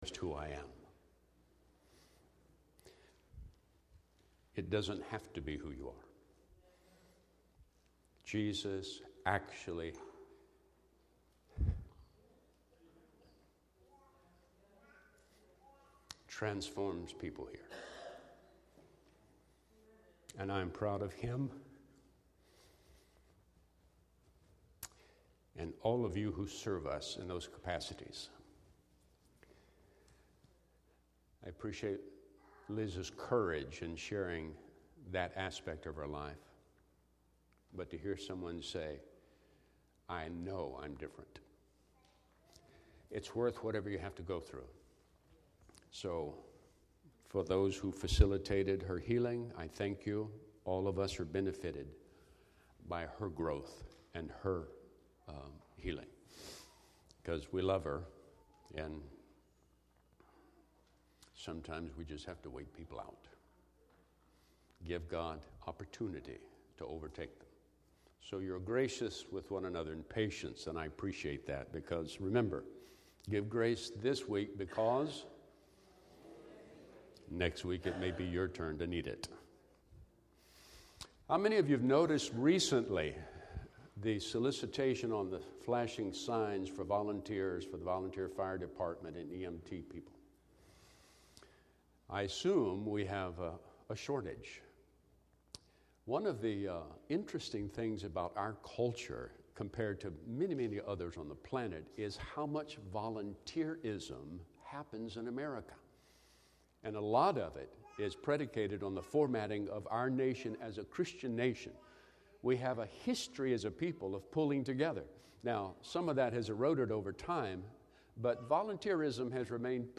Subject: Service Theme: Acceptance Scripture